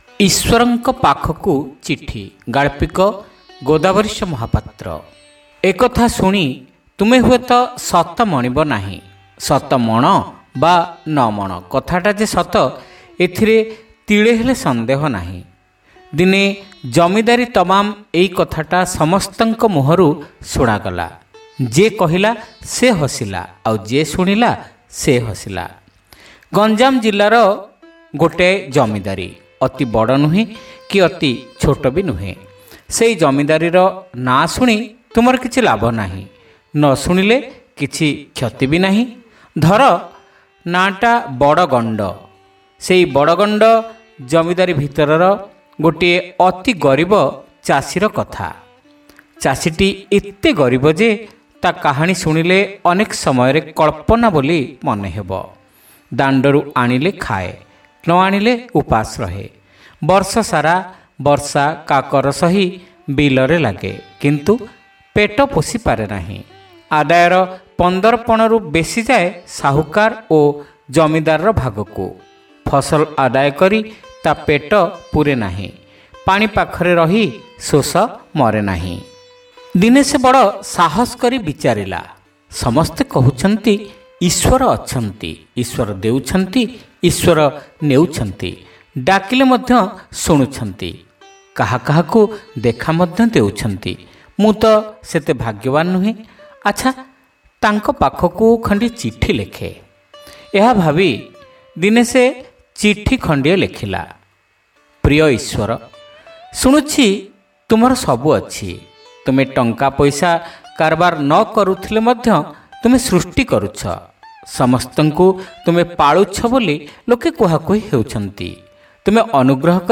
Audio Story : Iswarnka Pakhaku Chithi